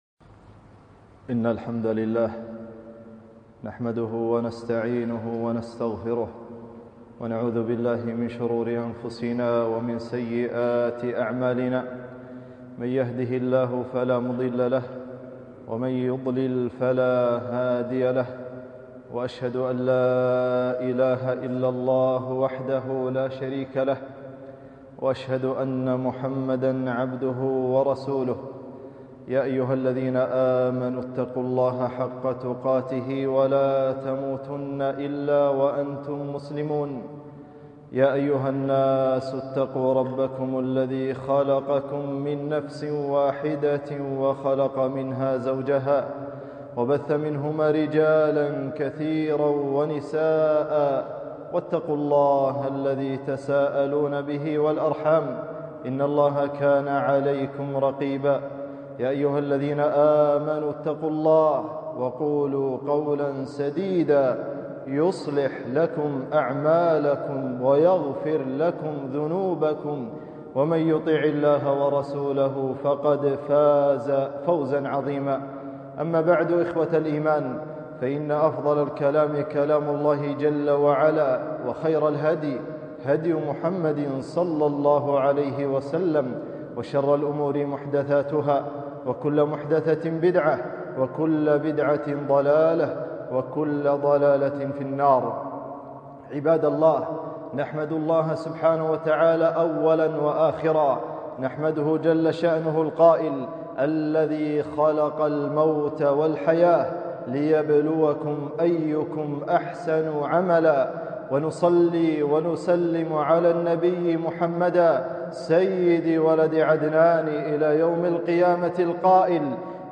خطبة - الزهد في الدنيا